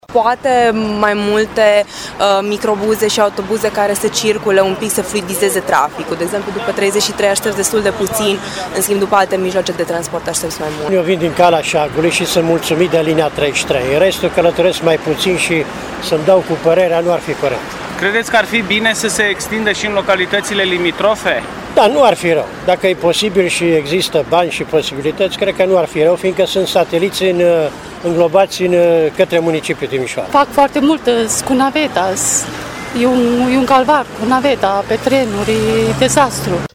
voxuri.mp3